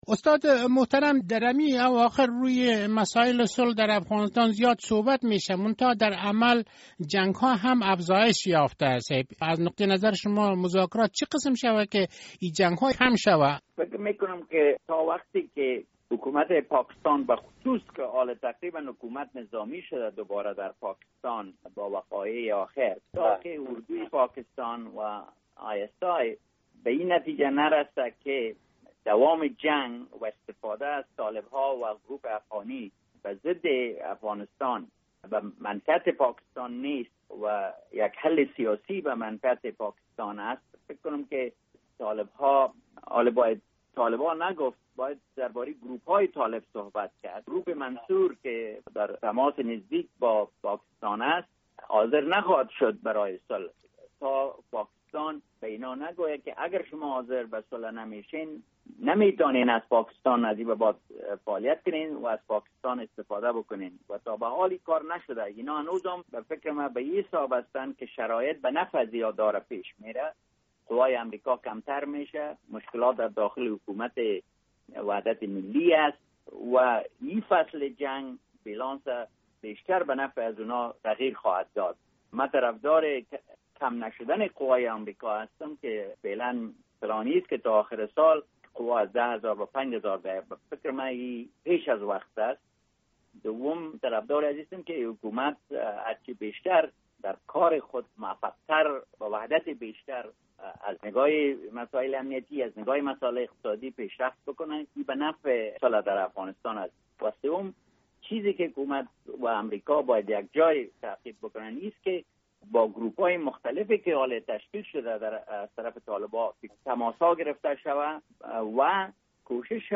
مصاحبه - صدا
زلمی خلیل زاد، سفیر پیشین امریکا در کابل و بغداد